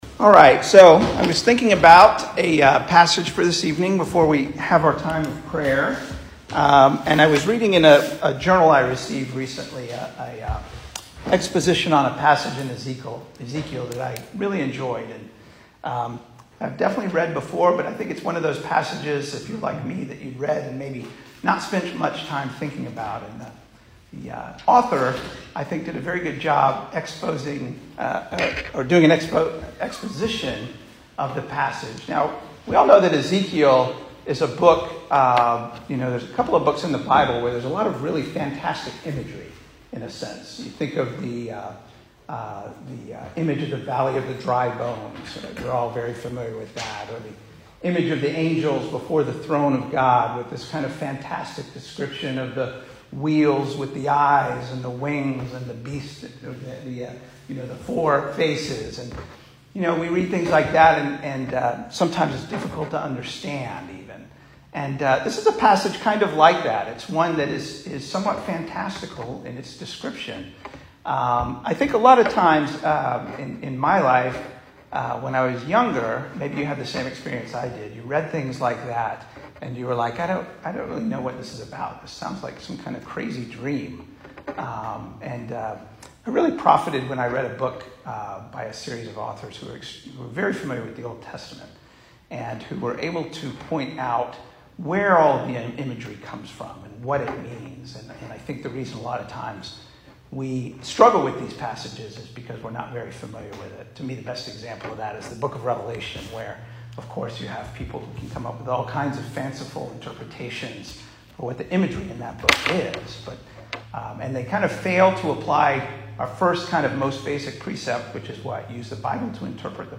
2022 Service Type: Weekday Evening Speaker